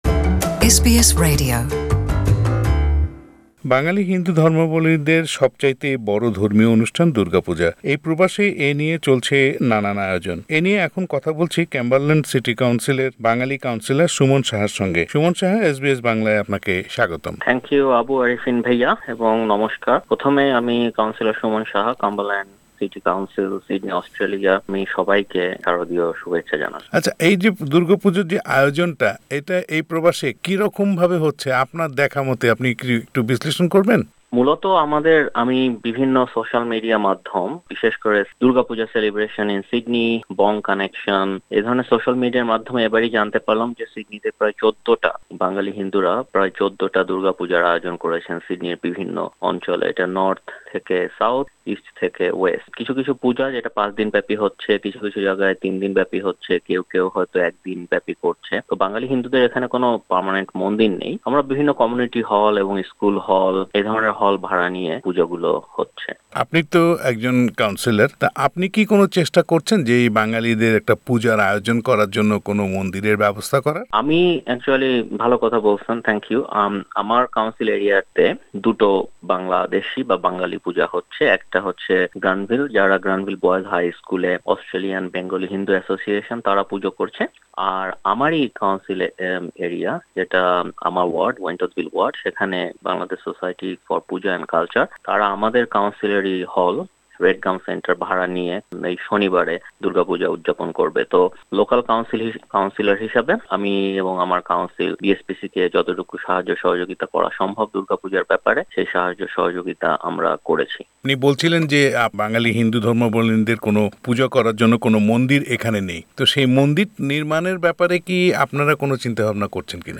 বাঙালি হিন্দু ধর্মাবলম্বীদের সবচেয়ে বড় ধর্মীয় অনুষ্ঠান দুর্গাপূজা শুরু হয়েছে। বিপুল উৎসাহ উদ্দীপনার মধ্যে দিয়ে অস্ট্রেলিয়া প্রবাসী বাংলাভাষী সম্প্রদায় পালন করছেন এই অনুষ্ঠানটি। প্রবাসের মাটিতে দুর্গাপূজা পালনের অভিজ্ঞতা নিয়ে এসবিএস বাংলার সাথে কথা বলেছেন কাম্বারল্যান্ড সিটি কাউন্সিলের কাউন্সিলর সুমন সাহা।